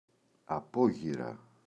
απόγυρα [a’poγira]